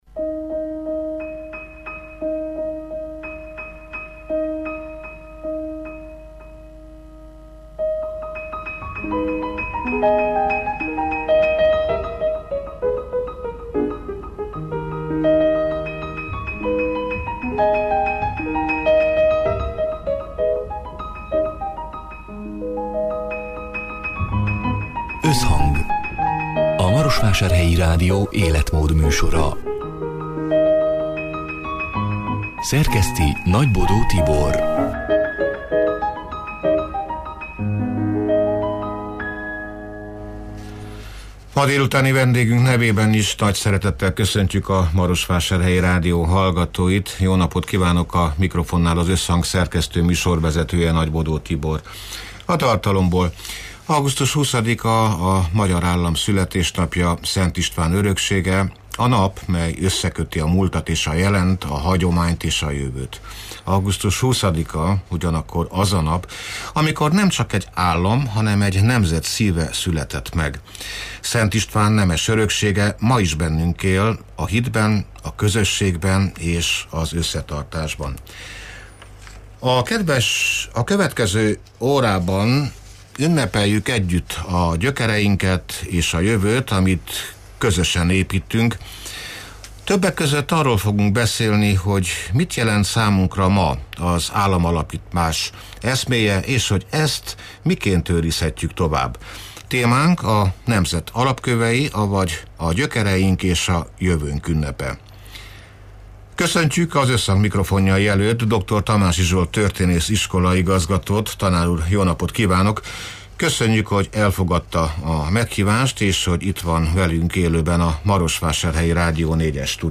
(elhangzott: 2025. augusztus 20-án, szerdán délután hat órától élőben)